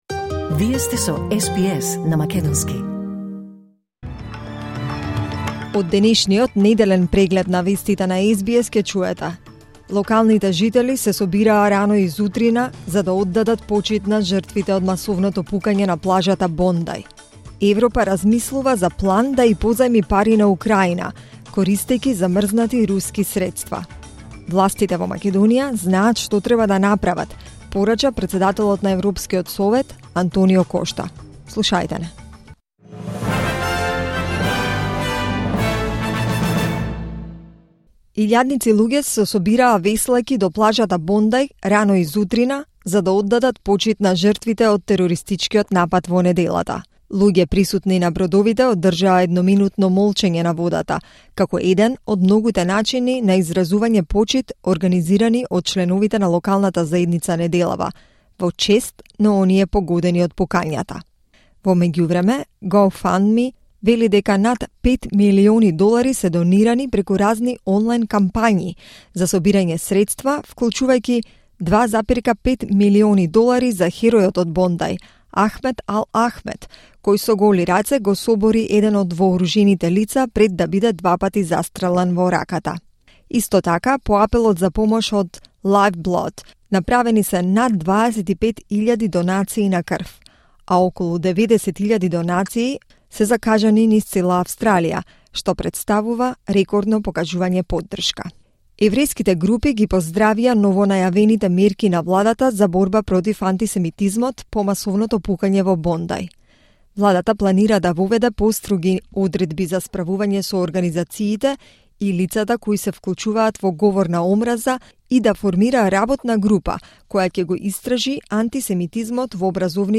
Преглед на неделните вести на СБС на македонски 19 декември 2025